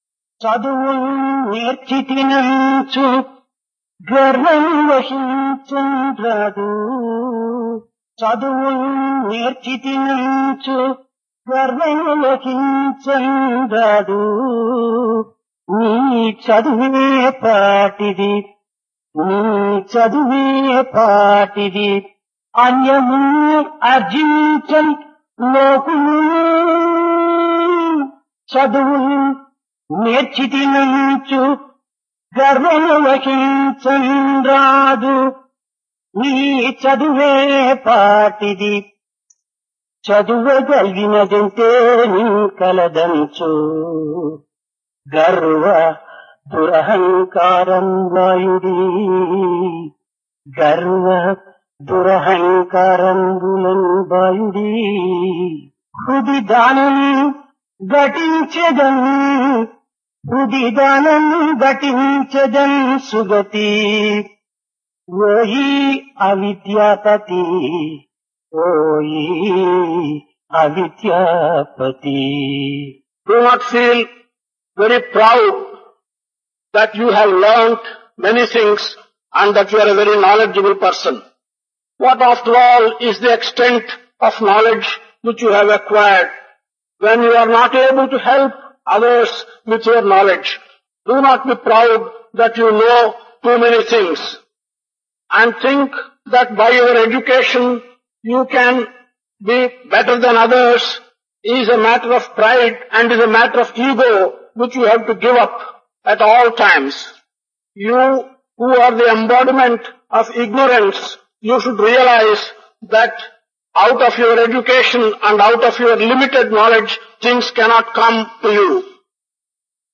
Divine Discourse of Bhagawan Sri Sathya Sai Baba, Summer Showers 1974
Place Brindavan Occasion Summer Course 1974 - Brahman